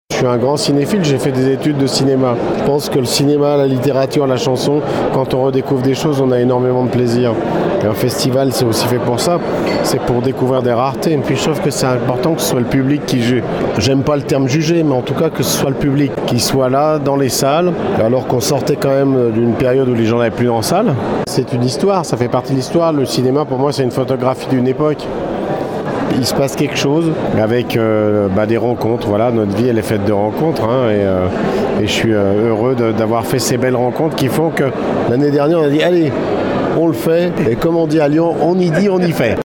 Le célèbre imitateur et humoriste que l’on a pu voir dans quelques films et téléfilms également nous parle de son rapport au cinéma français.